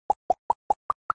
pop_combined2.ogg